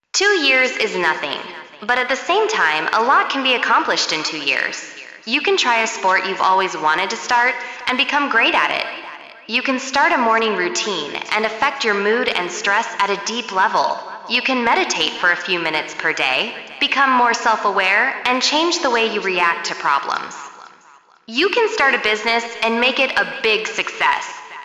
智东西实测，其还可以给音频添加场景氛围音，例如美国女高中生在广播中演讲
生成的音频不但可以清晰准确地念出文字，还有母语者很地道的停顿、语调。